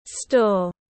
Store /stɔːr/